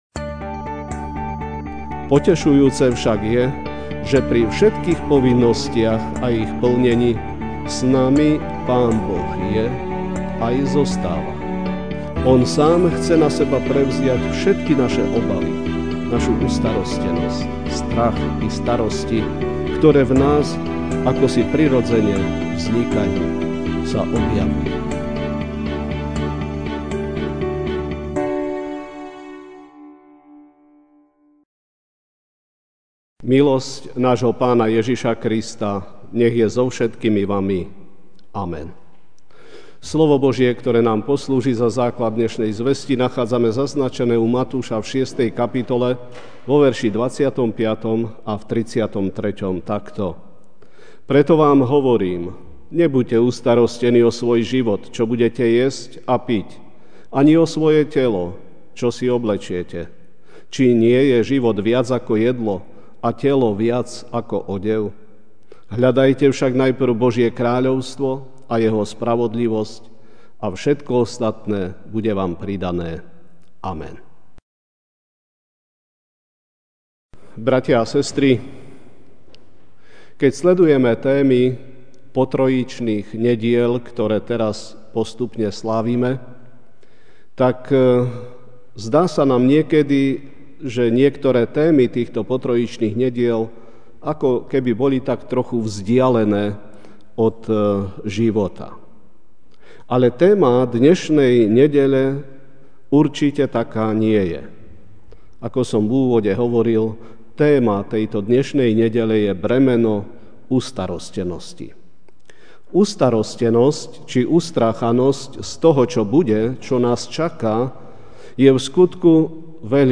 Večerná kázeň: Ustarostenosť: Nie sme na to sami (Mt. 6, 25 a 33) Preto vám hovorím: Nebuďte ustarostení o svoj život, čo budete jesť alebo piť, ani o telo, čím sa budete odievať.